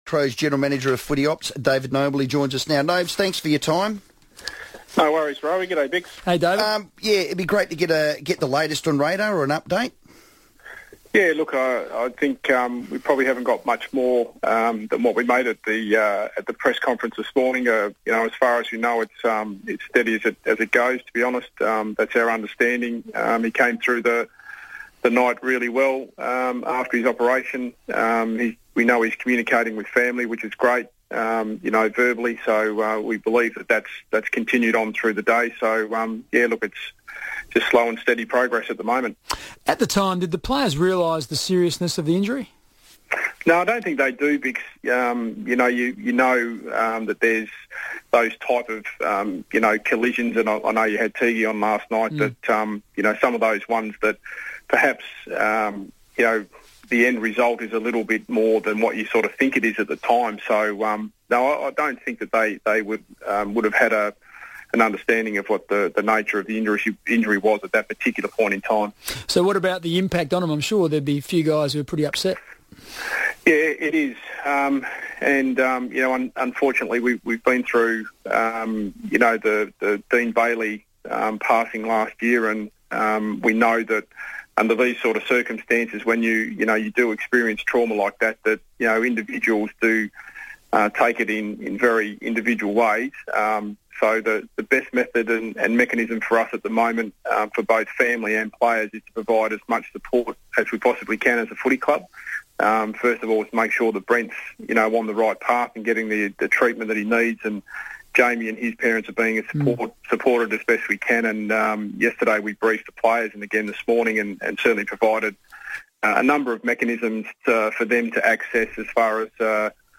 spoke on FIVEaa's afternoon Sports Show